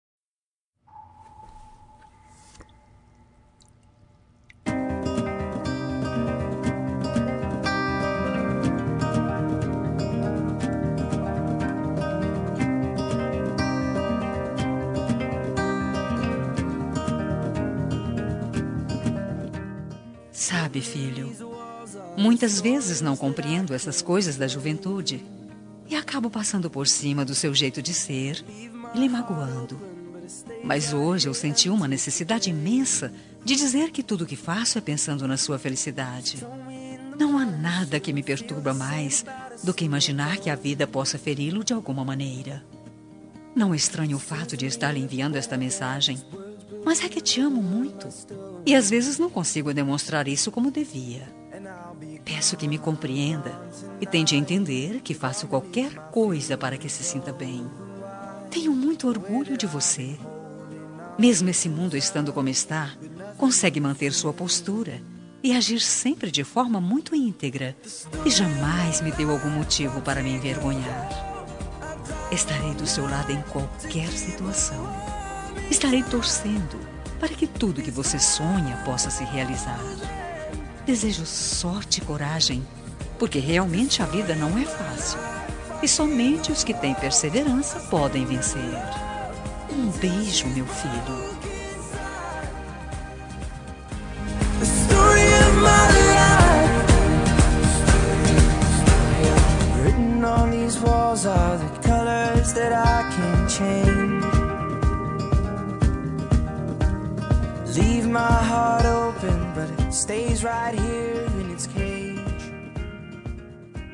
Homenagem para Filho – Voz Feminina – Cód: 8129